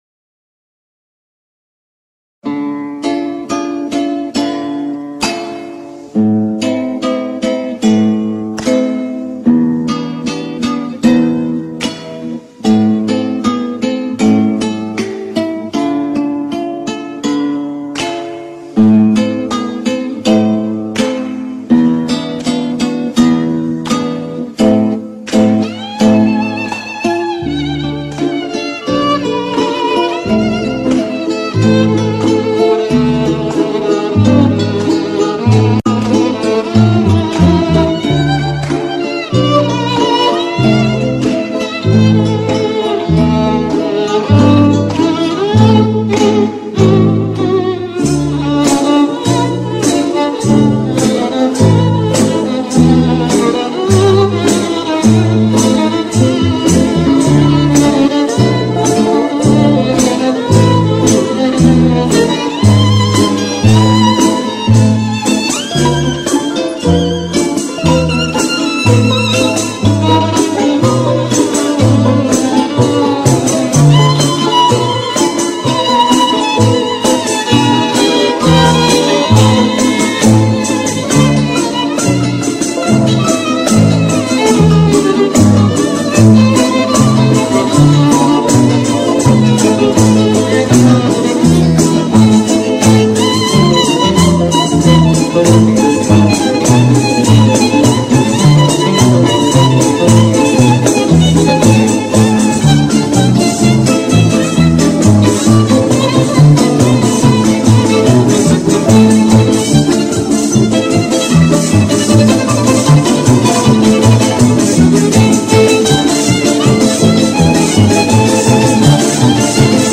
Two Guitars – Russian Gypsy Music
Two-Guitars-Russian-Gypsy-Music.mp3